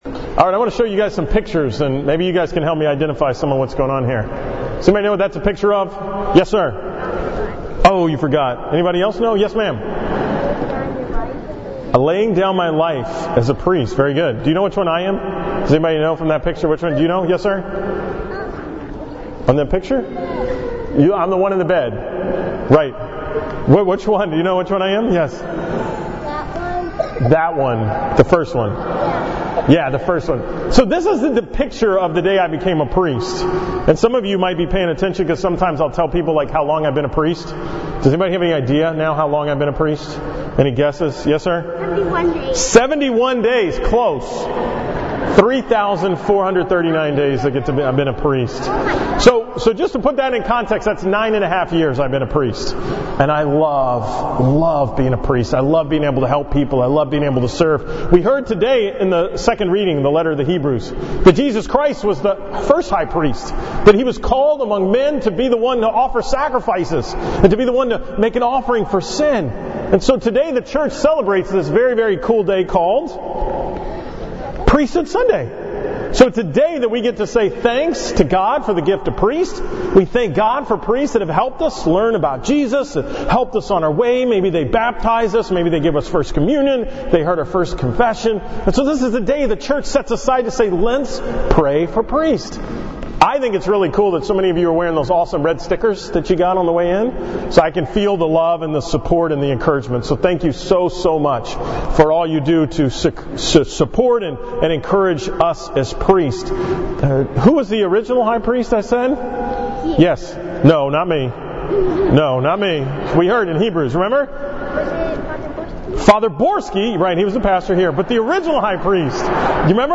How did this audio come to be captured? From the 9 am Mass on Sunday, October 28th on the celebration of Priesthood Sunday